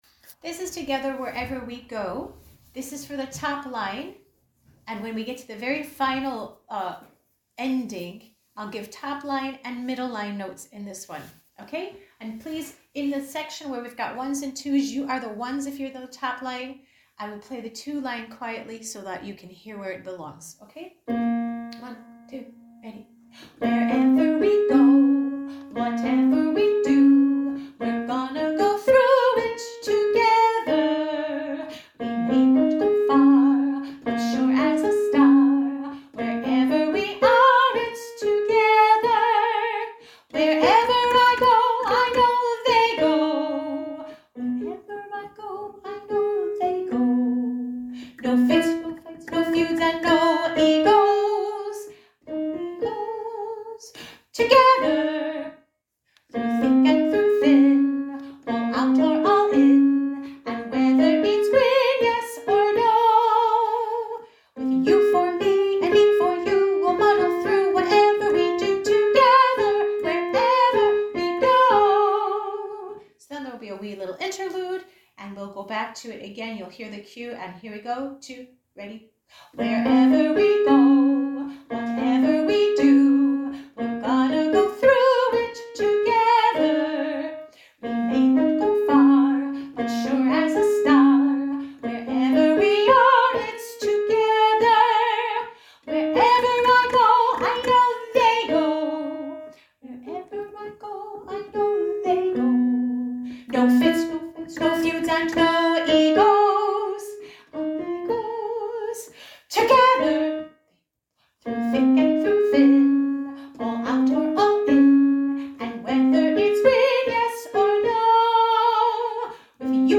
together-wherever-top-and-mid-harmonies-1.mp3